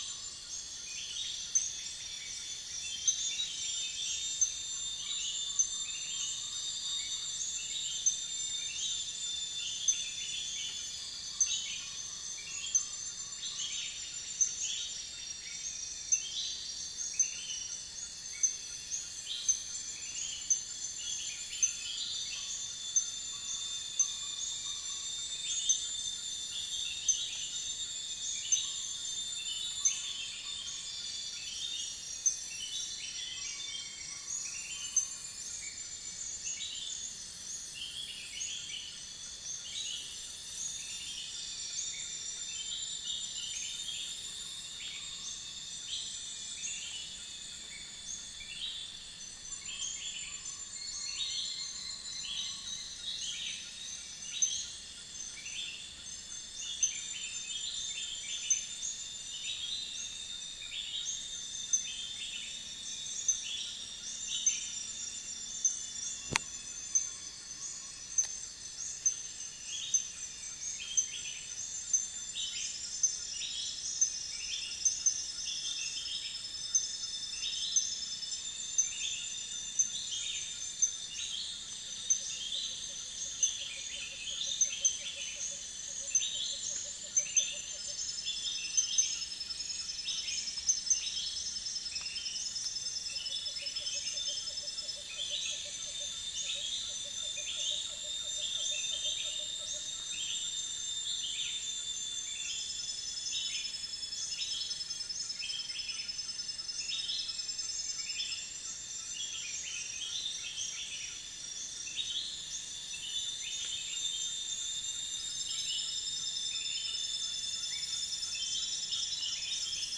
Mixornis gularis
Centropus sinensis
Pomatorhinus montanus
Trichastoma malaccense
Halcyon smyrnensis
ambient sound (background)